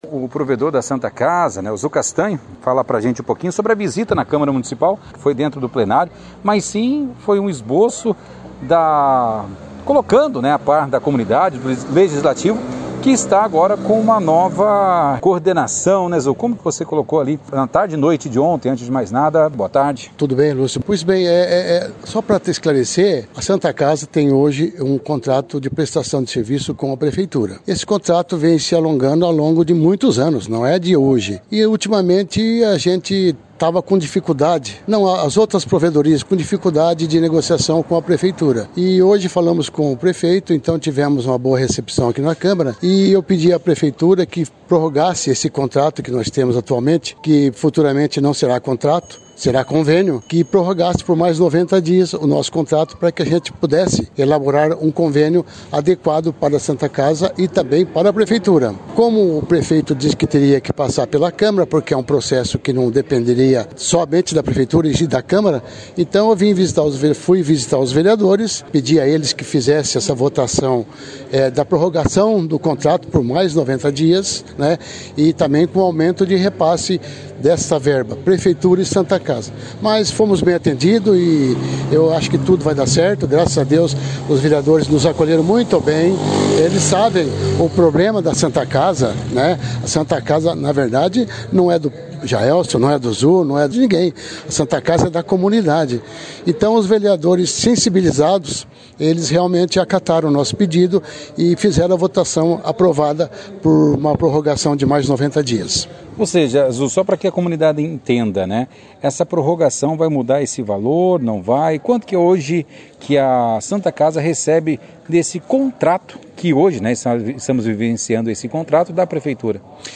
O provedor participou da 2ª edição do Jornal Operação Cidade desta terça-feira, 29/03, falando de sua presença na casa de leis.